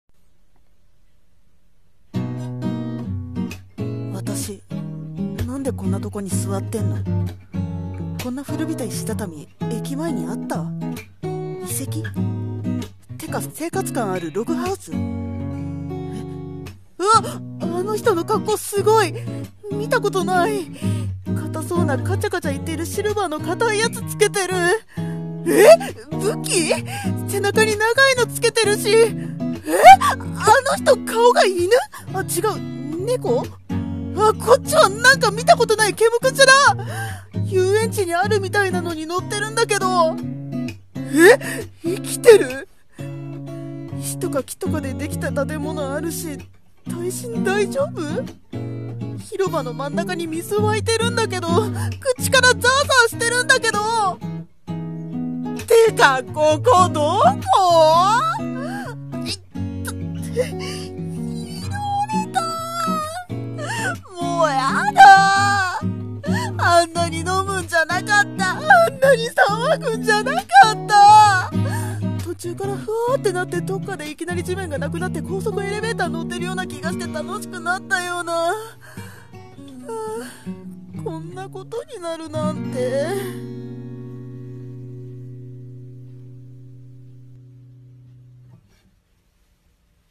五十音声劇台本○こんなことになるなんて BGM のんびりと 声劇/ラジオ